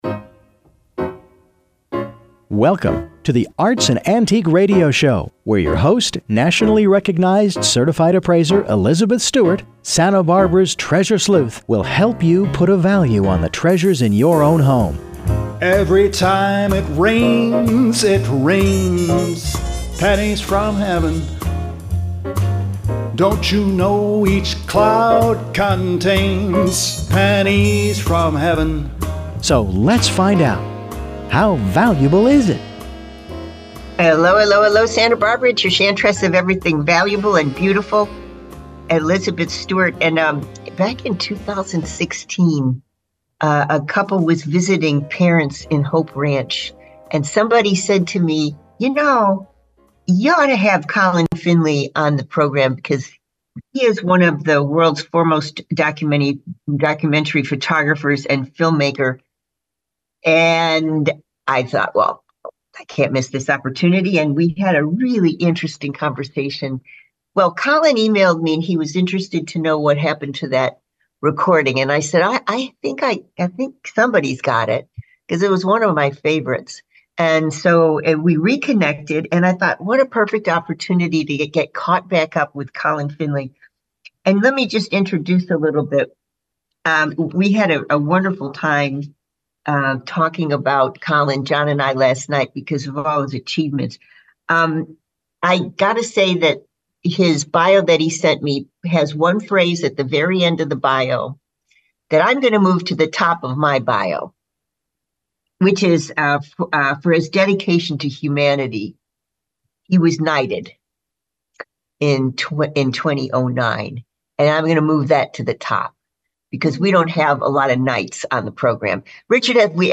World famous photojournalist Colin Finlay talks about his work.